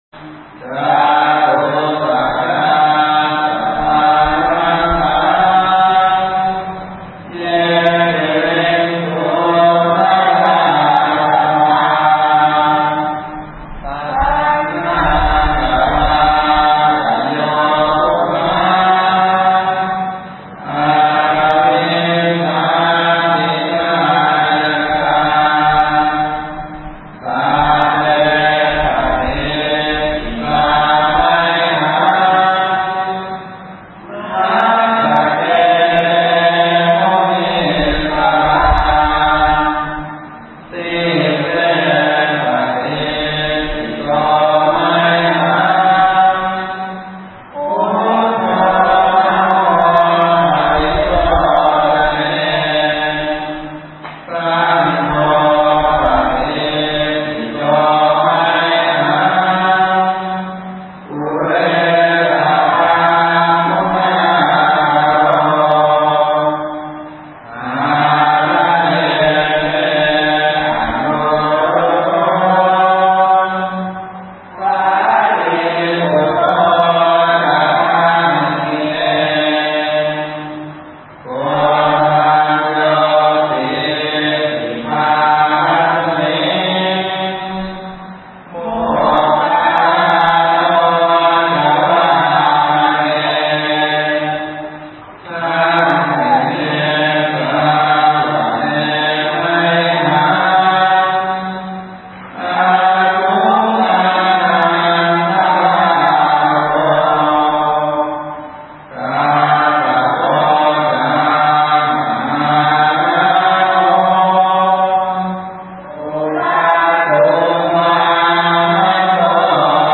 お堂に響くお経